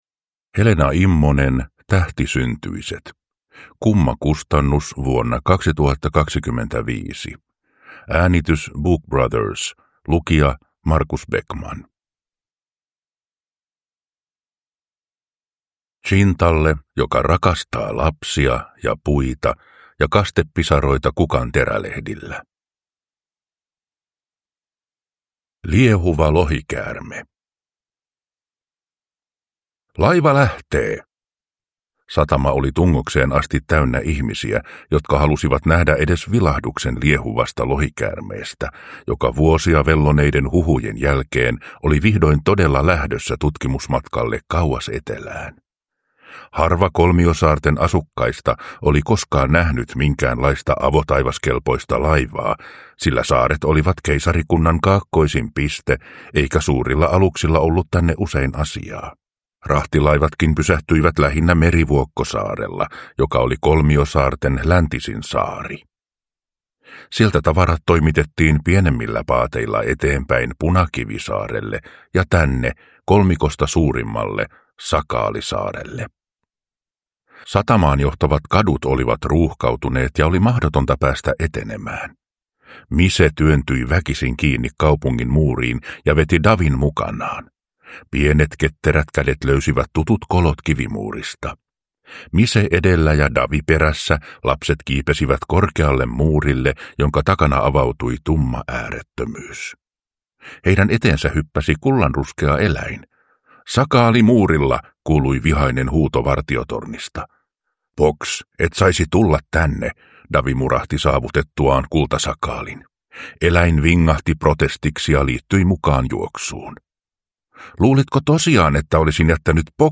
Tähtisyntyiset – Ljudbok